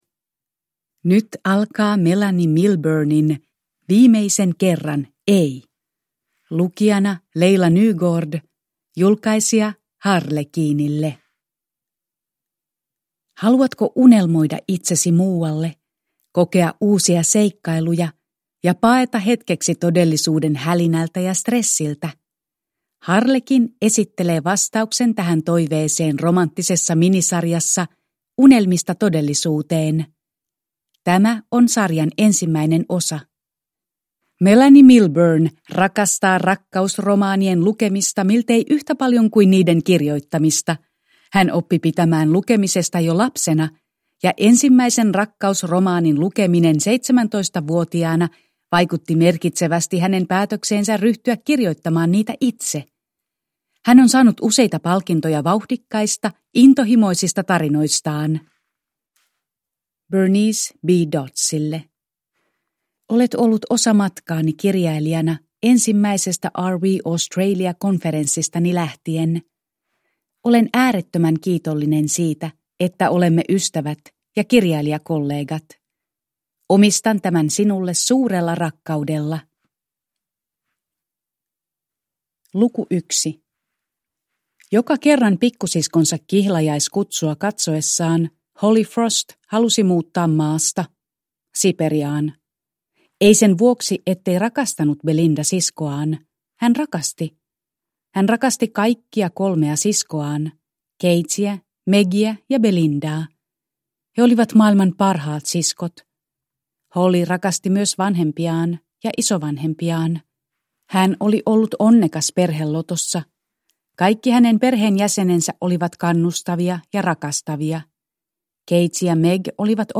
Viimeisen kerran: ei! – Ljudbok – Laddas ner